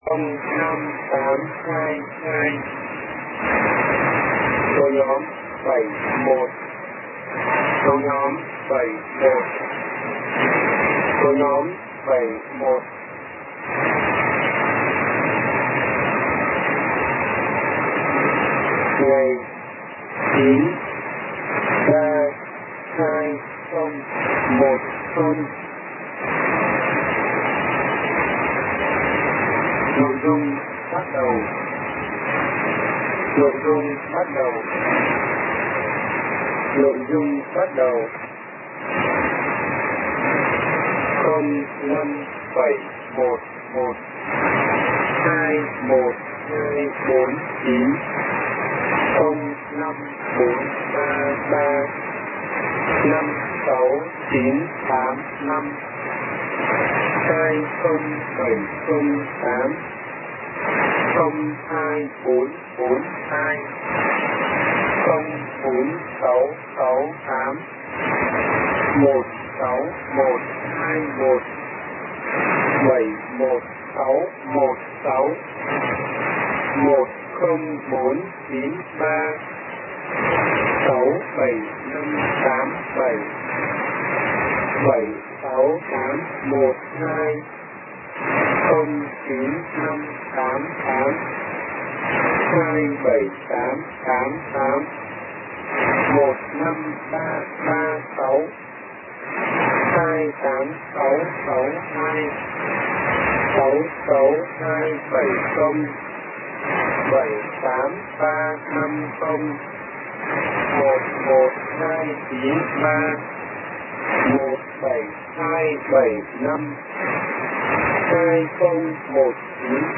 Every transmission received since then has been a male voice (OM), the female (YL) voice has not been heard again.
message body consisting of 30 of the five figure number groups.